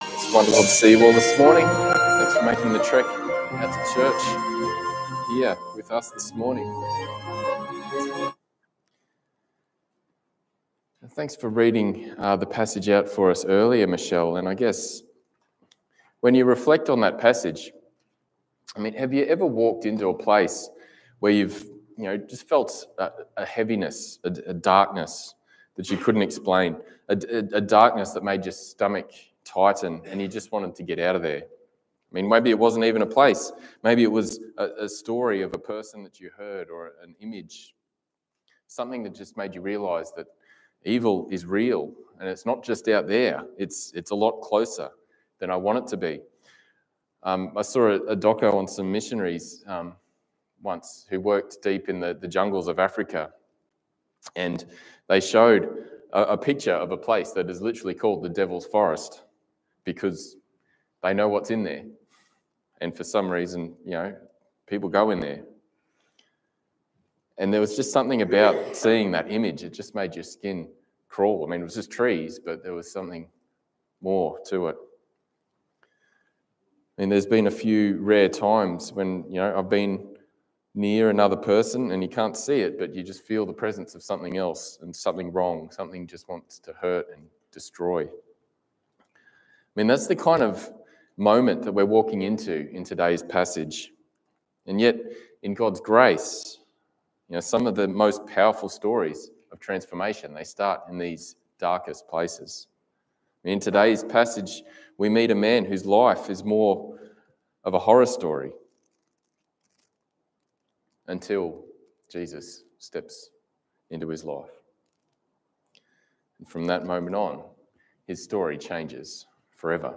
Passage: Luke 8:26-39 Service Type: Sunday Morning